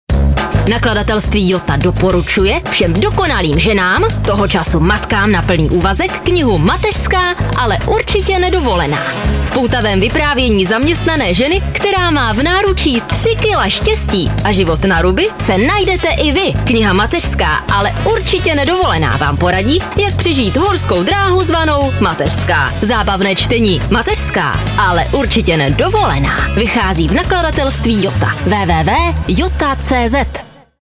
Ukázka 3 – Reklama